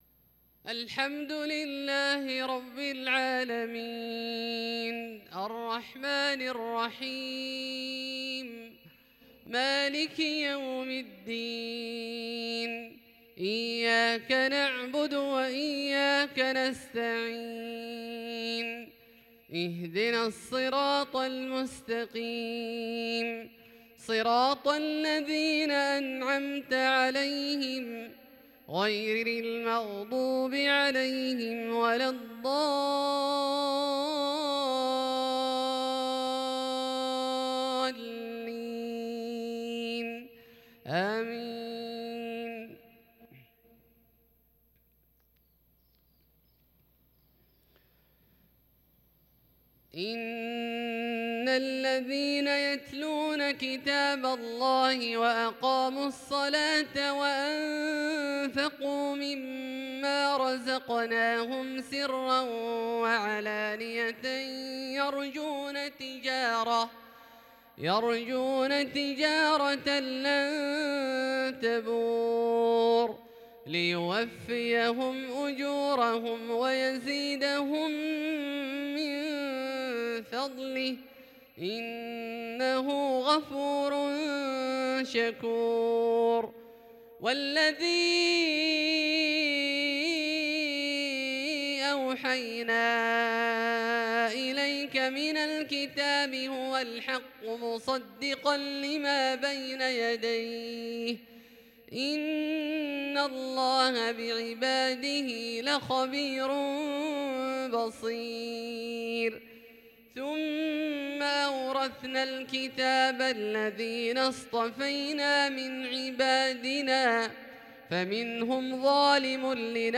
عشاء 3-3-1442 هـ من سورة فاطر(29-45) > ١٤٤٢ هـ > الفروض - تلاوات عبدالله الجهني